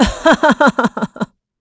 Valance-Vox_Happy4.wav